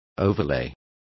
Complete with pronunciation of the translation of overlay.